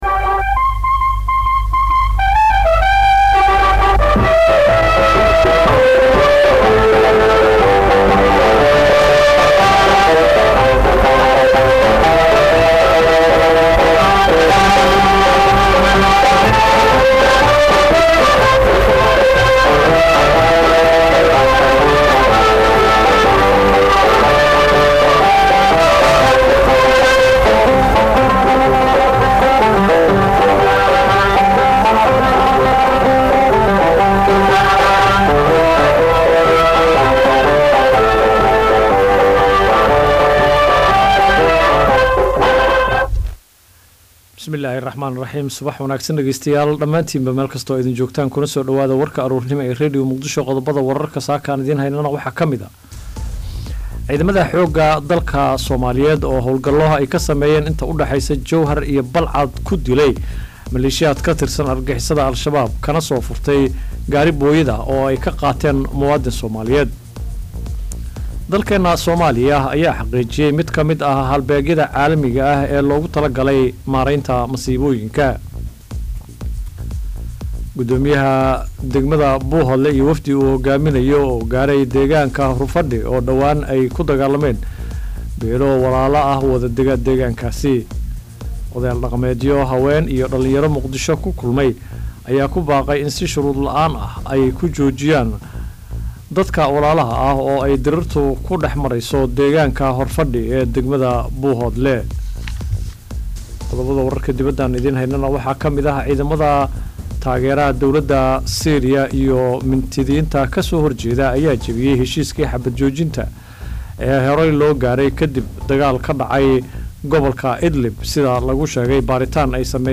Dhageyso warka subax ee Radio Muqdisho.